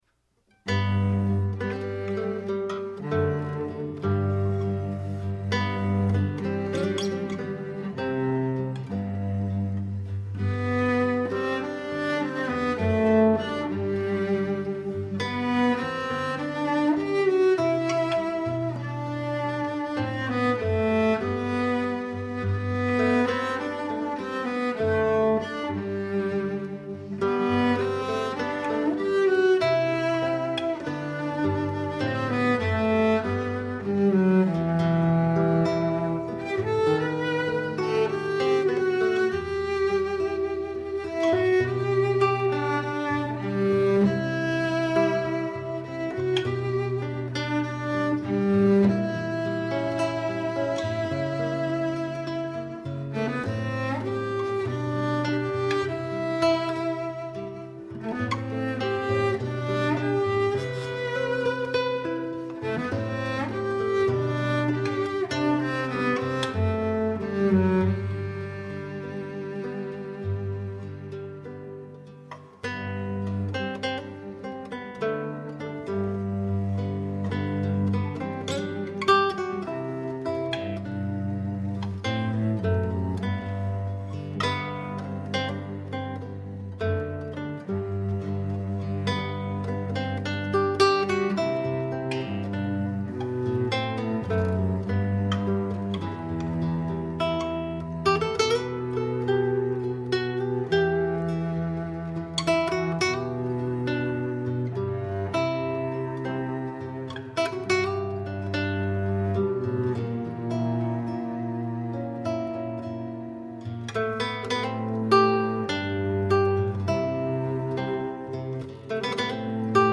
Guitar and Cello
I provide a harmonic background using my classical guitar.